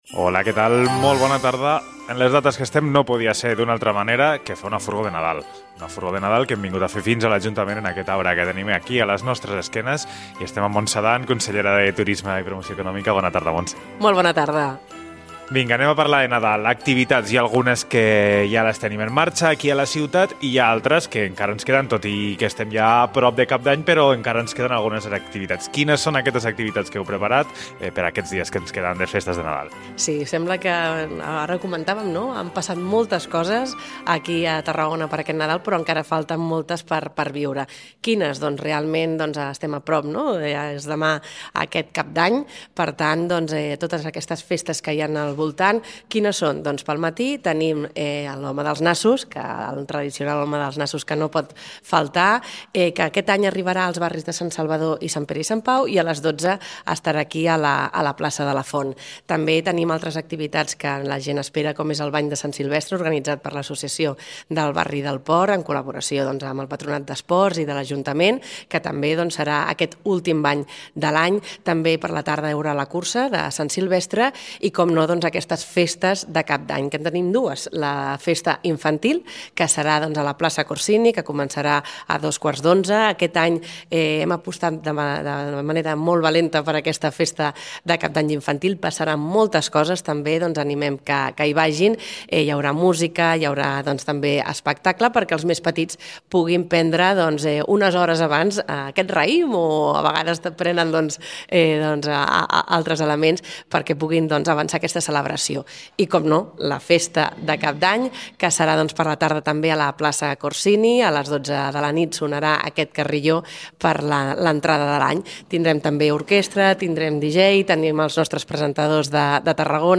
Avui a La Furgo ens hem impregnat de l’esperit nadalenc des del cor de la ciutat, davant de l’arbre de Nadal de l’Ajuntament, per parlar de totes les propostes que encara queden per viure aquests dies tan especials.